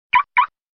звонкие
сигнал машины
Звук открытия/закрытия машины